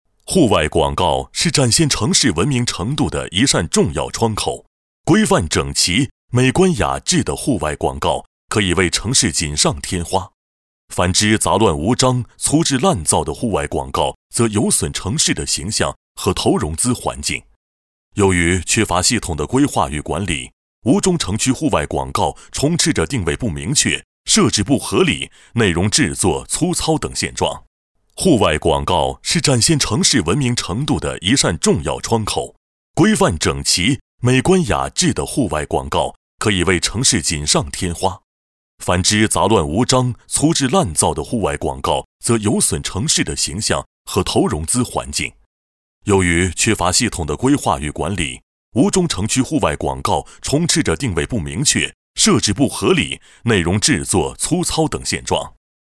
国语青年积极向上 、亲切甜美 、男课件PPT 、工程介绍 、60元/分钟男S310 国语 男声 【大气浑厚】华为P20产品介绍 积极向上|亲切甜美